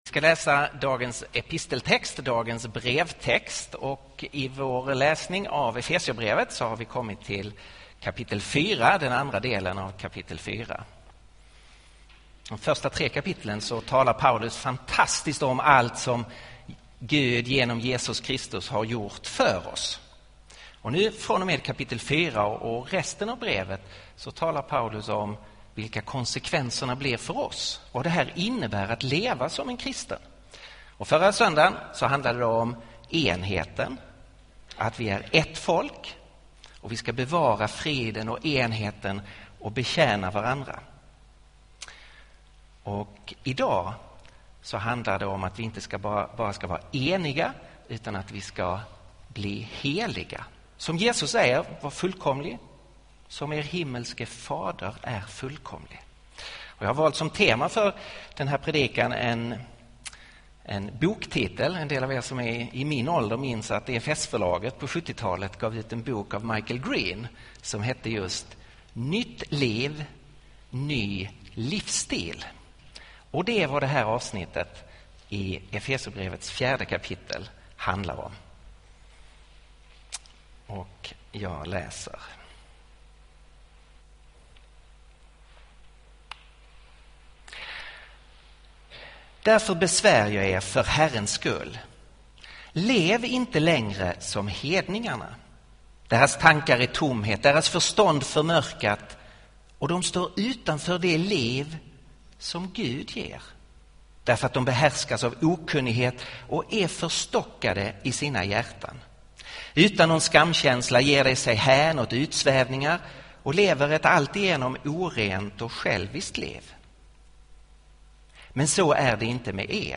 Inspelad vid Betlehemskyrkan i Stockholm 2014-03-09.